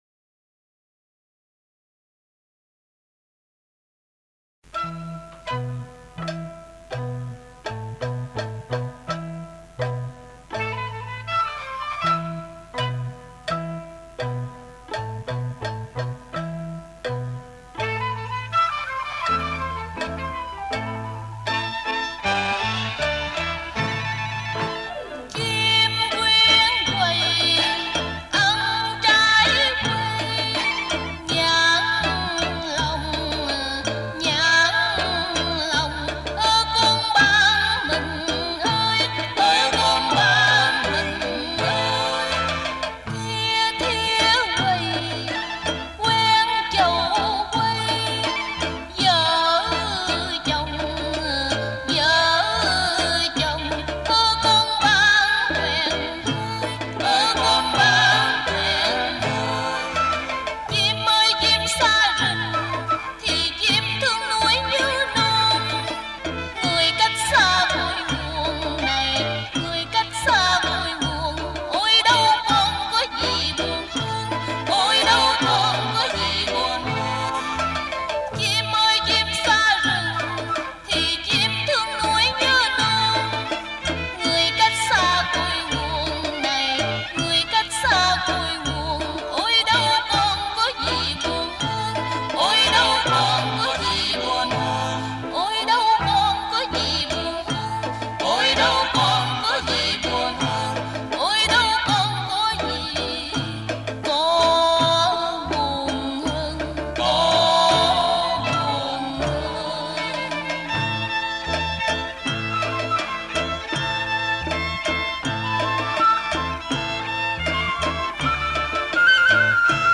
Lý Chim Quyên – Dân ca do Phạm Duy sưu tập
Trình Bày: Thanh Tuyền